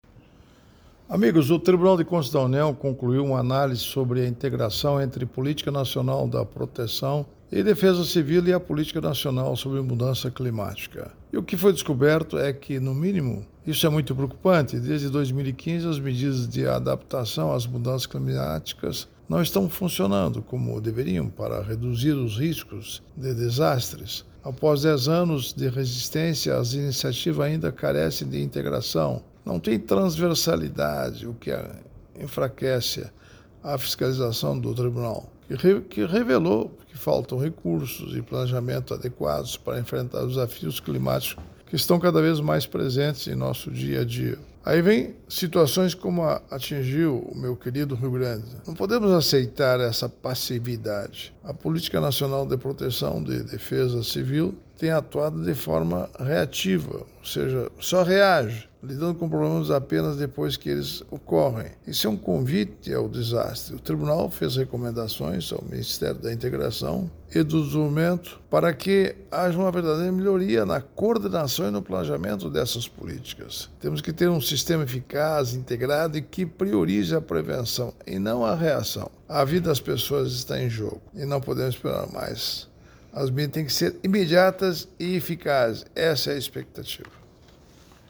Comentário de Augusto Nardes, ministro do TCU.